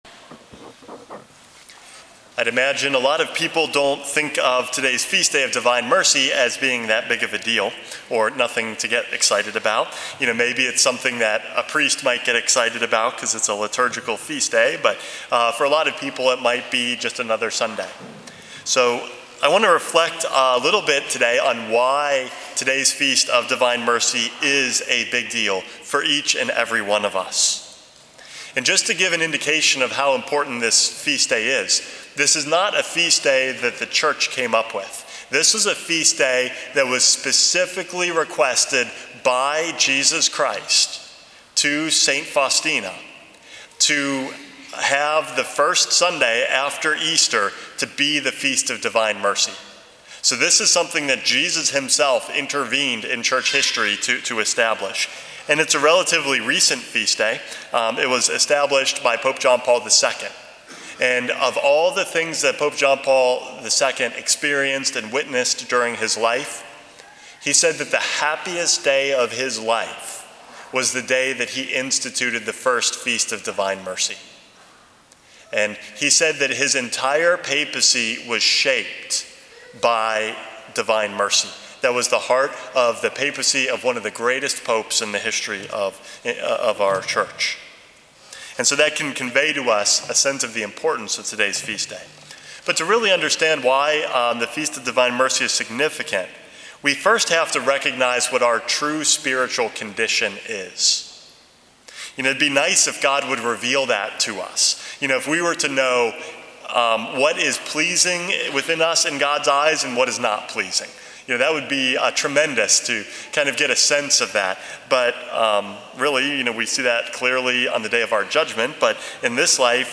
Homily #447 - Divine Mercy Matters